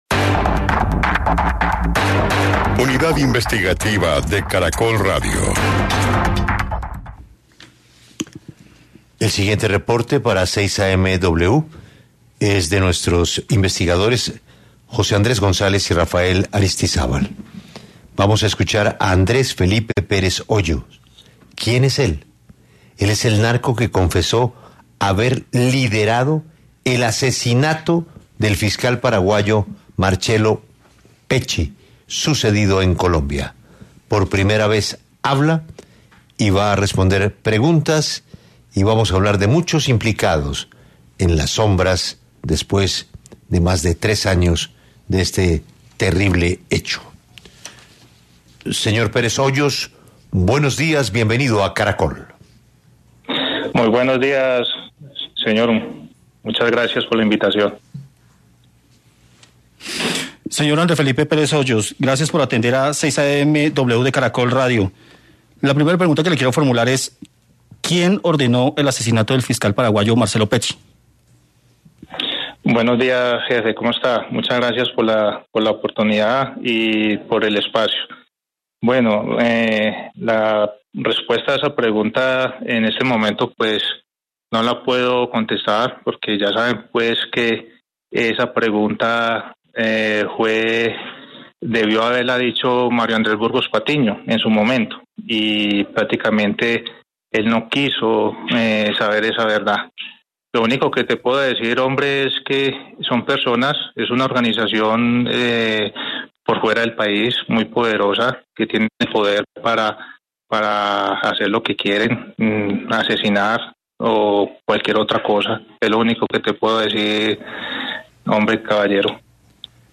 habló en 6AM W de Caracol Radio